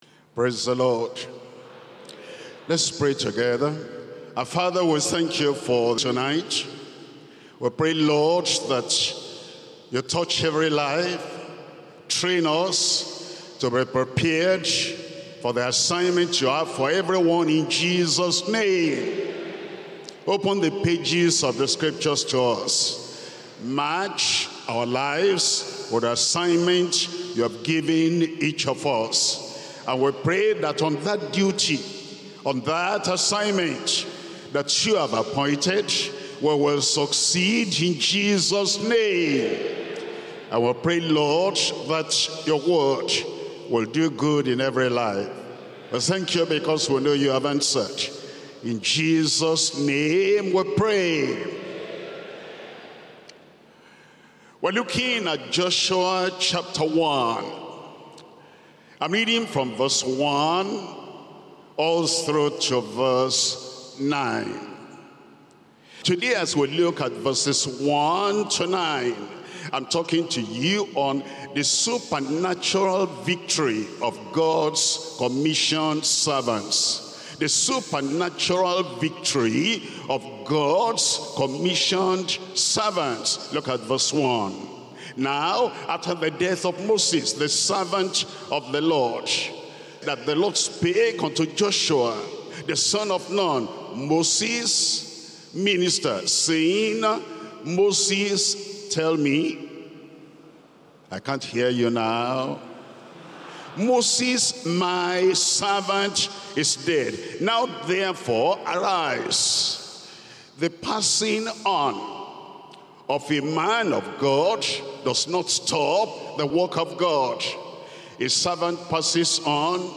Sermons - Deeper Christian Life Ministry